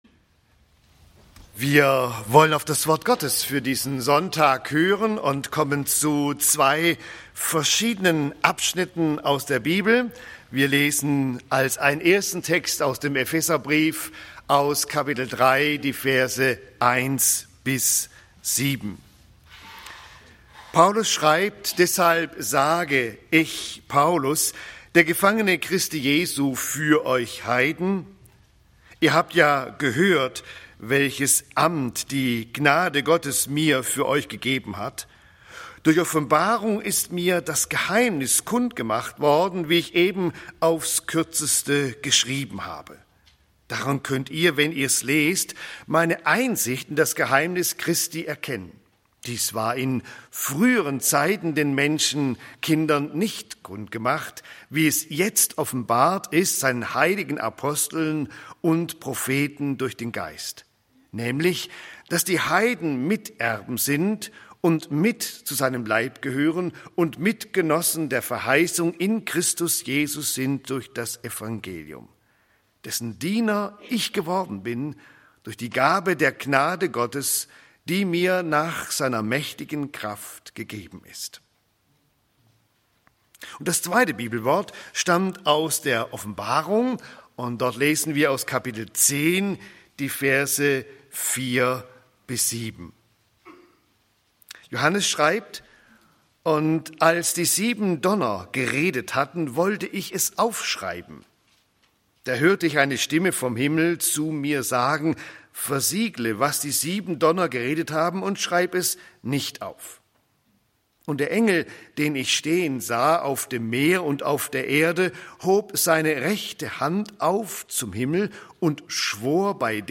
Das Geheimnis Gottes und die Auserwählten vor Grundlegung der Welt (Eph. 3, 1-7; Offb. 10, 5-7) - Gottesdienst